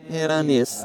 La riprova si ha ascoltando le registrazioni al contrario:
Villa Estense al contrario;